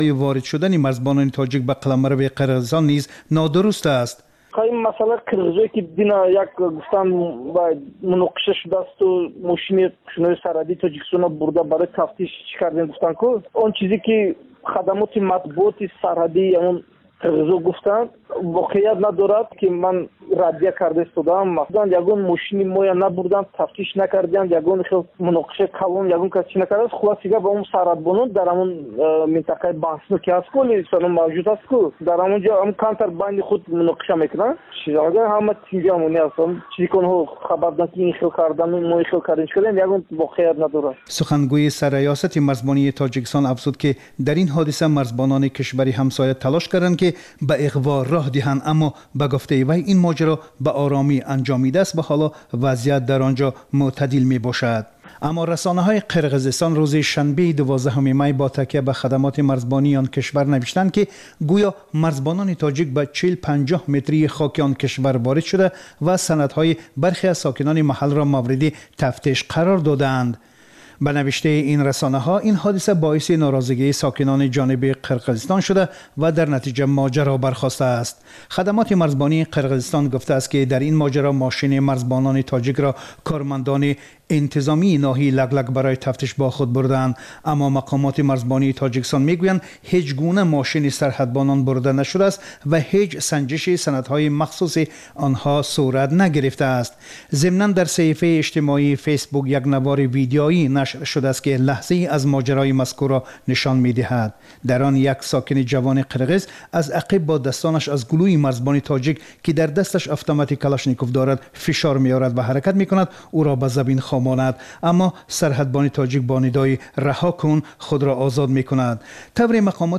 Гуфтори вижаи Радиои Озодӣ аз ҳаёти ҷавонони Тоҷикистон ва хориҷ аз он. Дар ин барнома таҳаввулоти ҷавонон аз дидгоҳҳои сиёсиву иҷтимоӣ, фарҳанги маданӣ тақдим мешавад